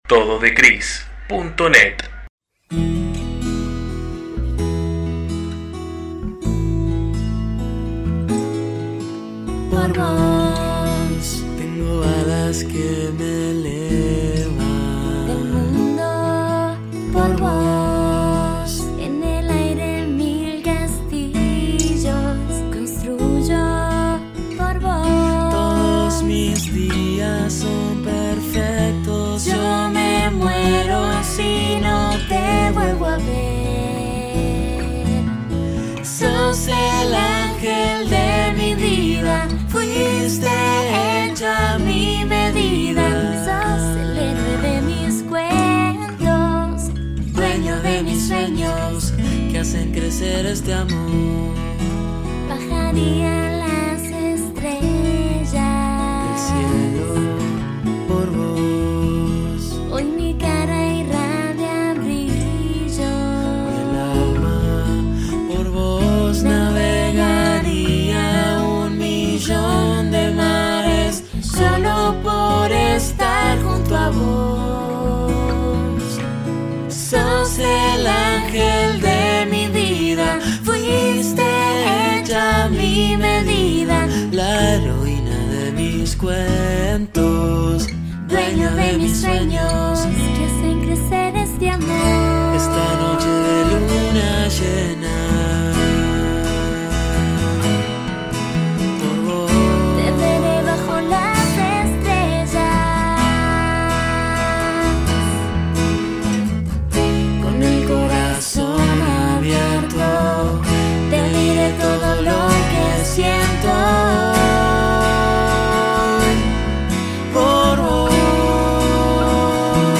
12 Por Vos (Acústico)